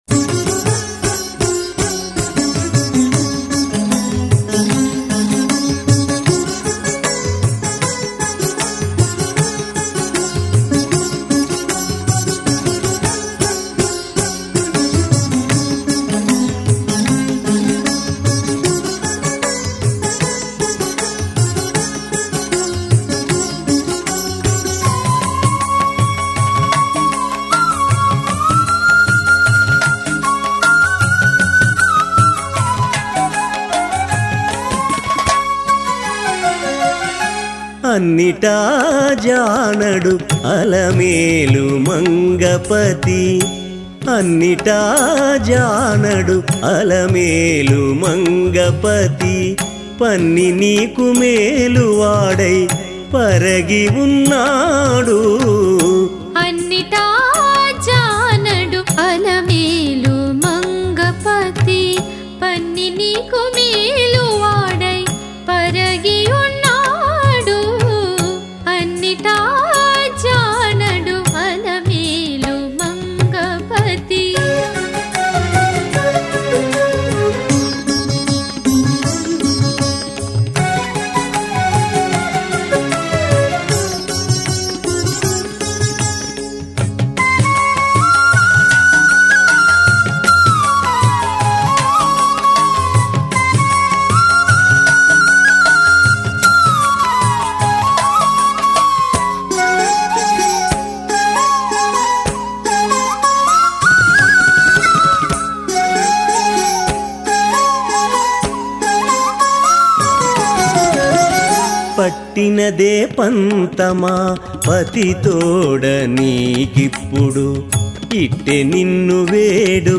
సంగీతం